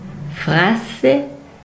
Frasses (Freiburger Patois
Frp-greverin-Frassè.ogg.mp3